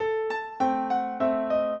minuet8-7.wav